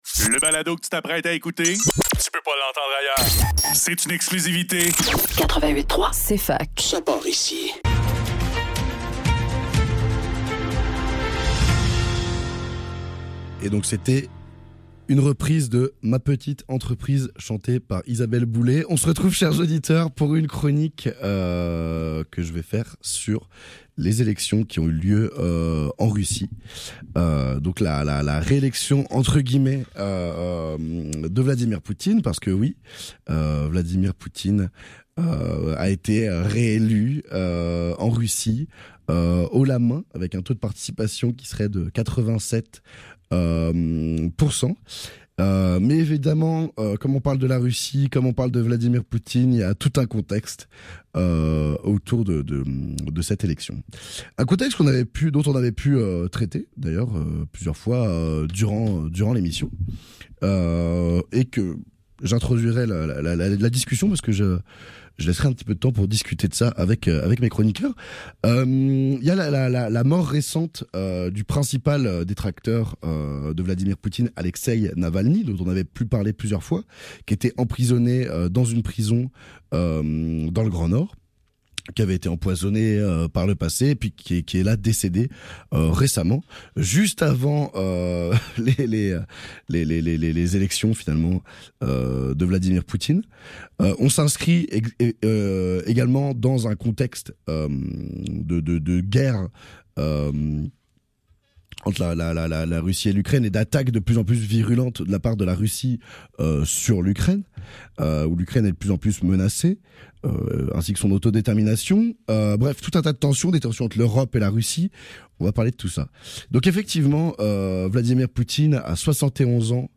Le NEUF - Entrevue avec Isabelle Maréchal - 20 mars 2024